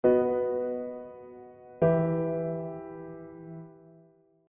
Perfect Cadence B - Em
The perfect cadence, a progression from the dominant to the tonic chord, is the most decisive and powerful cadence.